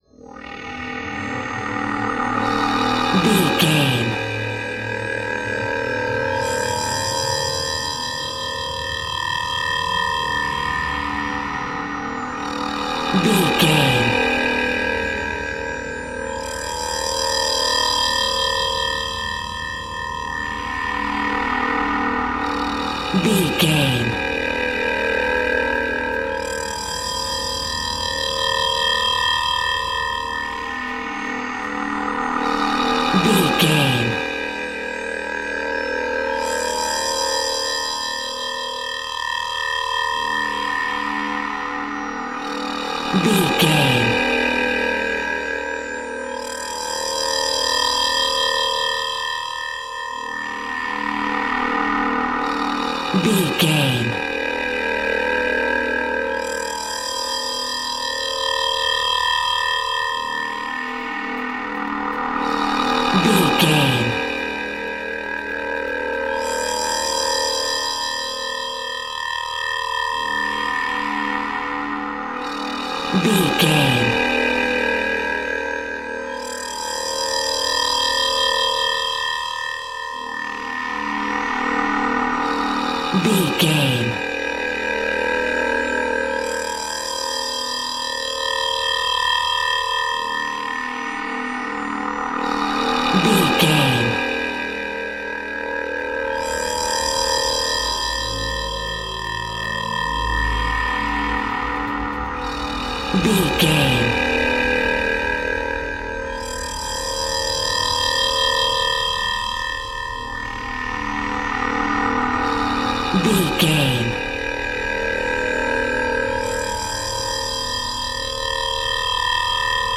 Atonal
Slow
ominous
haunting
eerie
Horror synth
Horror Ambience
electronics
synthesizer